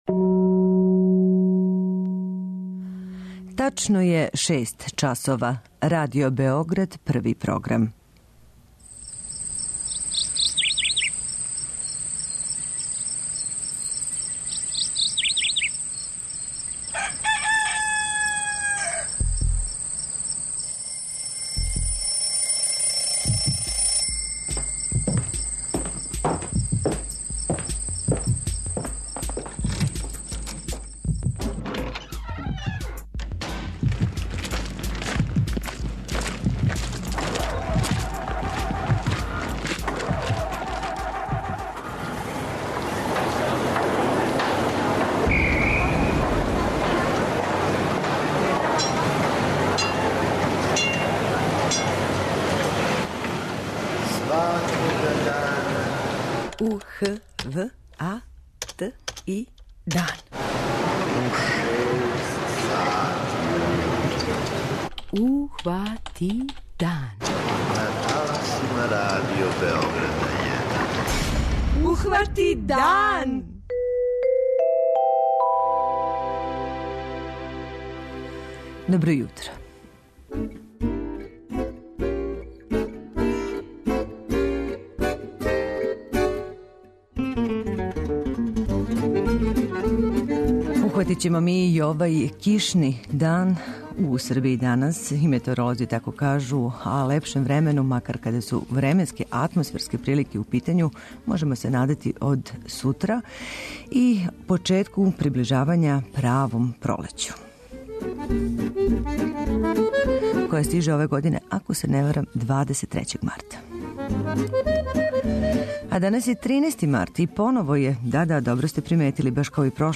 преузми : 85.96 MB Ухвати дан Autor: Група аутора Јутарњи програм Радио Београда 1!